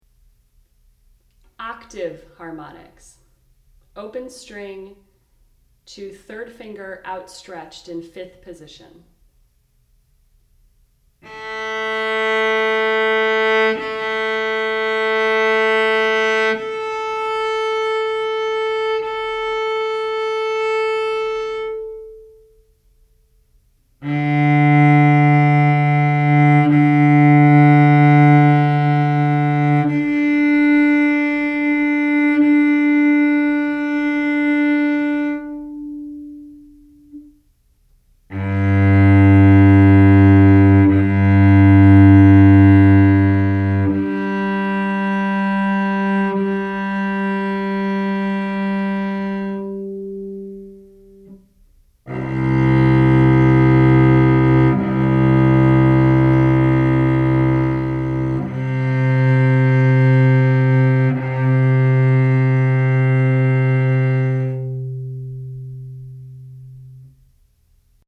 Below are some fun things you can do with the cello.
Harmonics – Octave Harmonics – Octave and Octave+5th
Harmonics.mp3